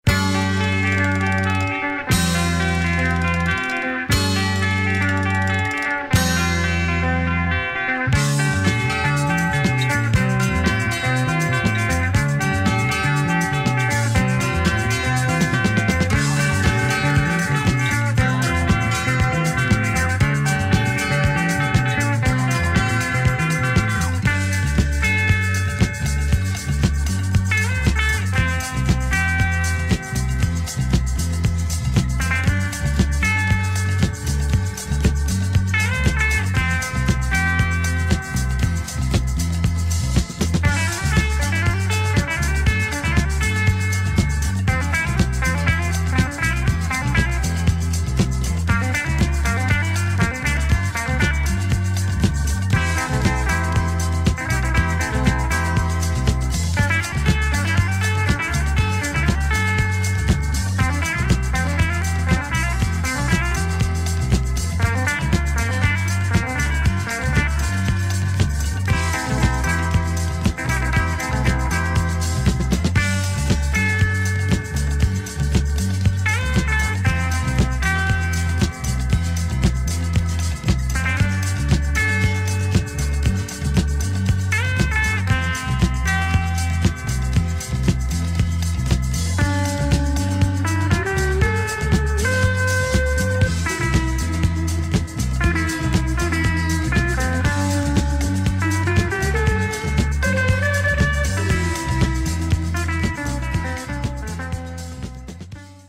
Killer Indian Ocean psych, funk and disco